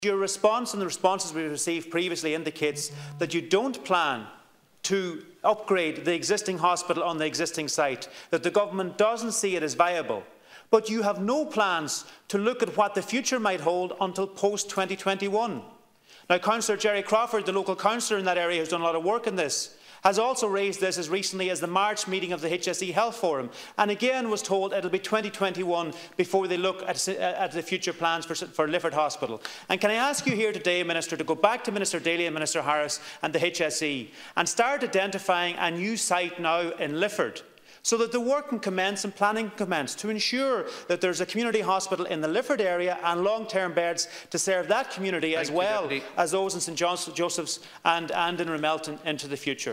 In a cross-party representation in the Dail yesterday, Donegal Deputies Pat the Cope Gallagher, Pearse Doherty and Charlie McConalogue pressed Minister Finian McGrath for a commitment that work at the hospitals would commence as soon as possible.
To which Deputy McConalogue told the Minister that an urgent review of that decision is needed: